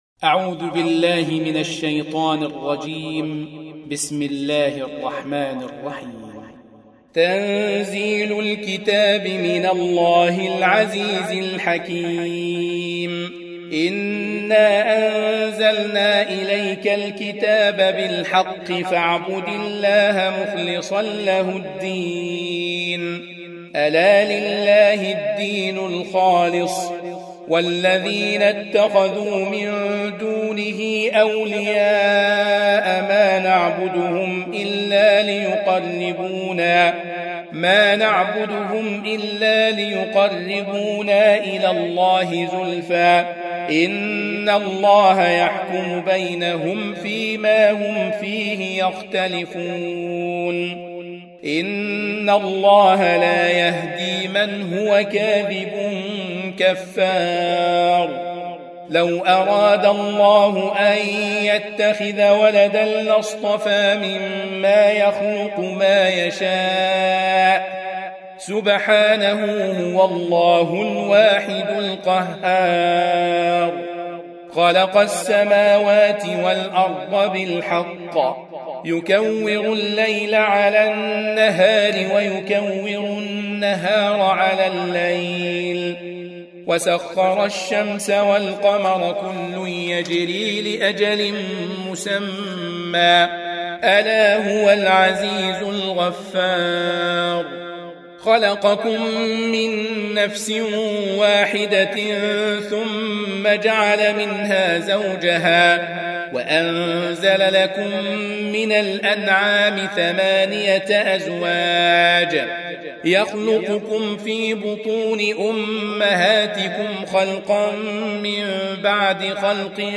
39. Surah Az-Zumar سورة الزمر Audio Quran Tarteel Recitation
Surah Repeating تكرار السورة Download Surah حمّل السورة Reciting Murattalah Audio for 39.